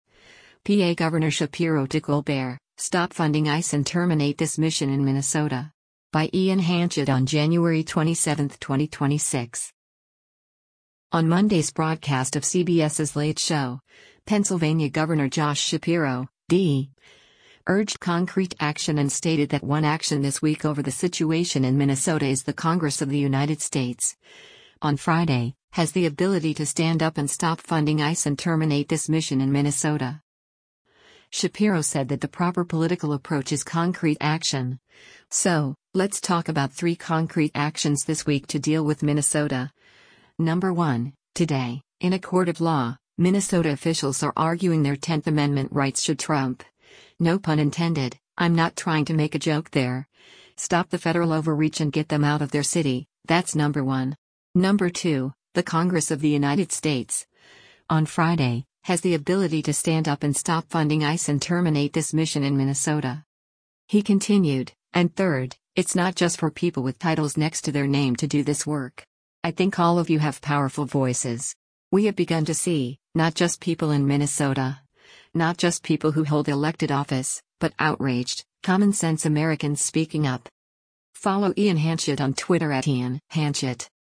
On Monday’s broadcast of CBS’s “Late Show,” Pennsylvania Gov. Josh Shapiro (D) urged “Concrete action” and stated that one action this week over the situation in Minnesota is “the Congress of the United States, on Friday, has the ability to stand up and stop funding ICE and terminate this mission in Minnesota.”